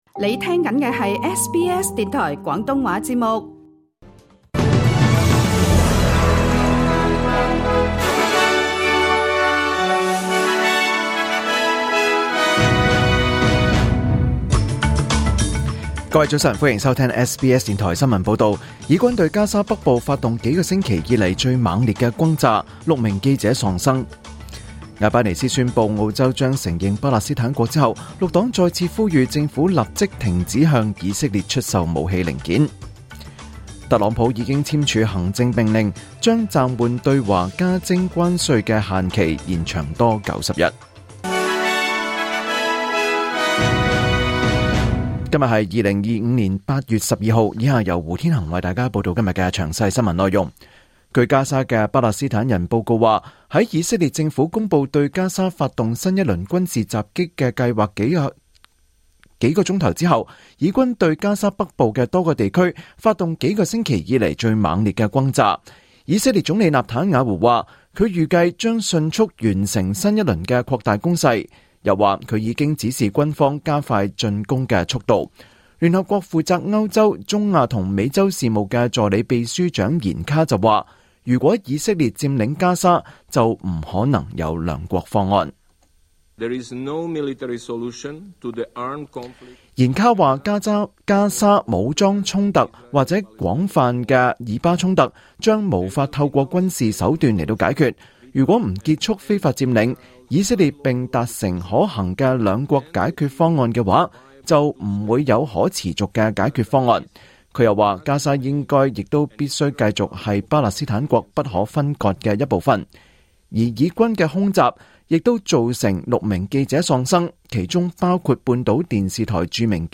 2025年8月12日SBS廣東話節目九點半新聞報道。